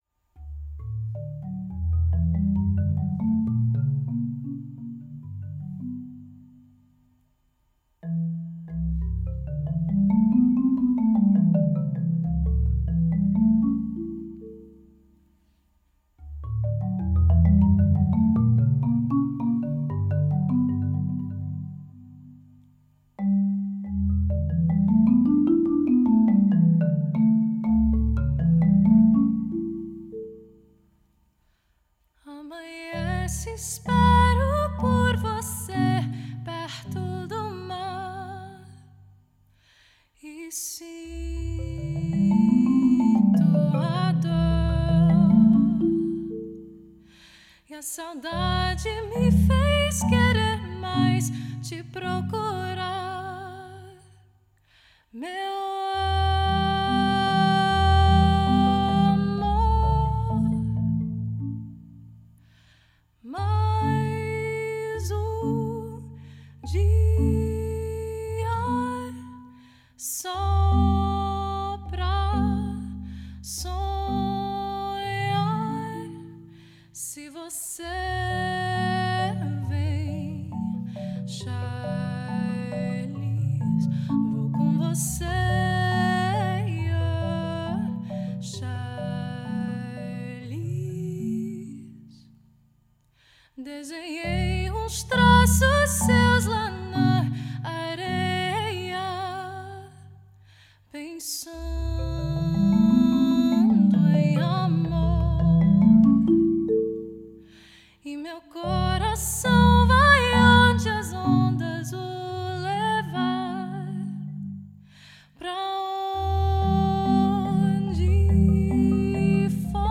for marimba and voice
Voicing: Marimba | Voice